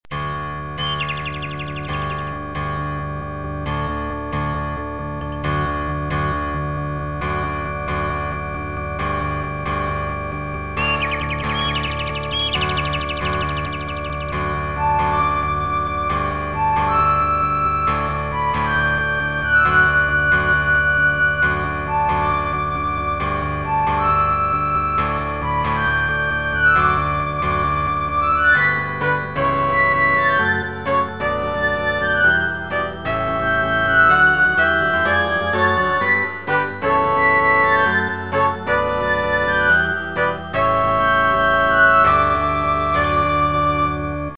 試聴用 MP3ファイル ループ再生になっておりますので、BGMなどの参考にしてください。
POINT 各所でテンポを変えて、印象付けました。
隣にはホルン担当。